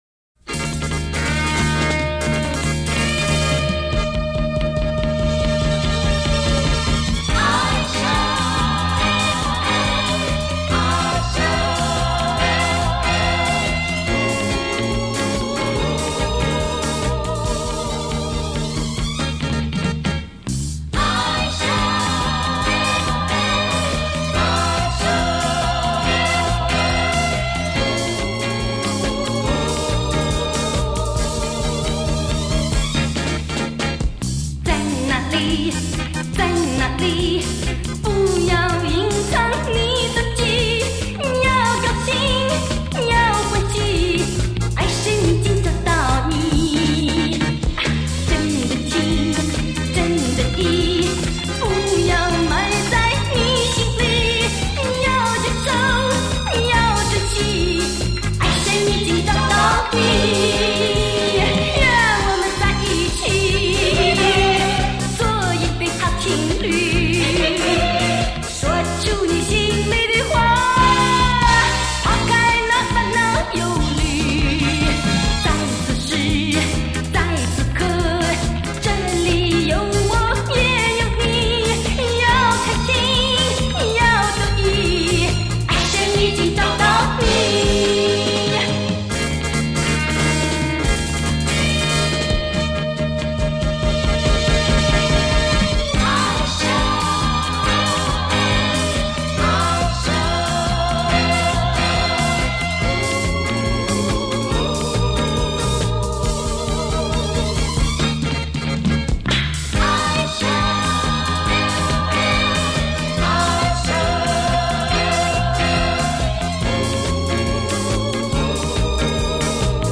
本系列采用近三十年间，最值得珍藏之原唱者母带所录制，弥足珍贵！